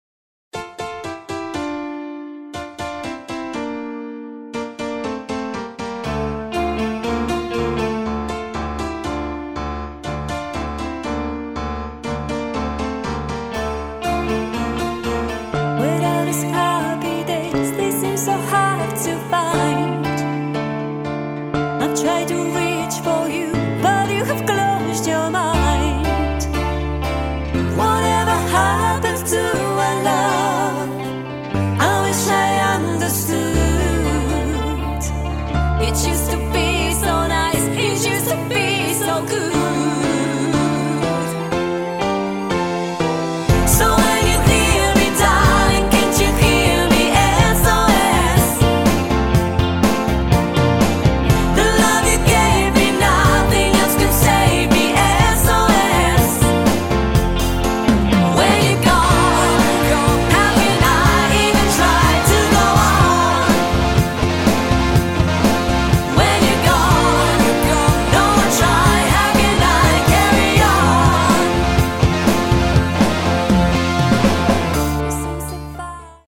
Tribute Acts